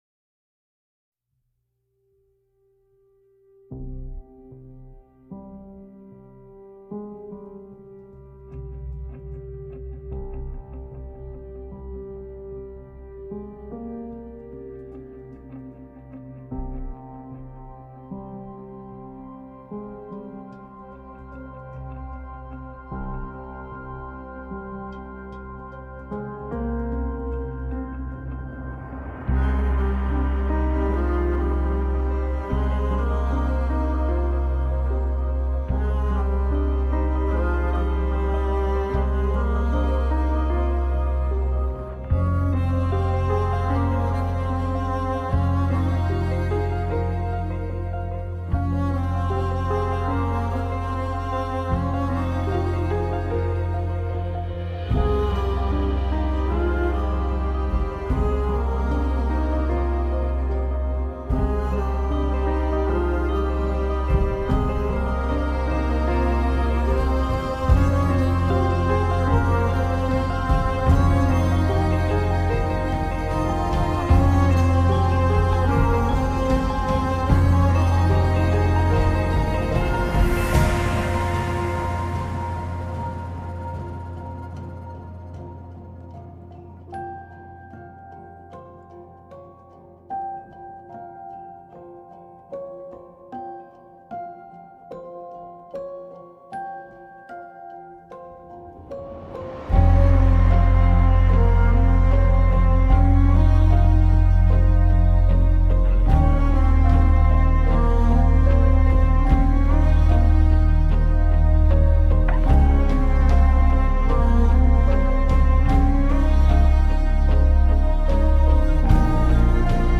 tema dizi müziği, duygusal hüzünlü heyecan fon müzik.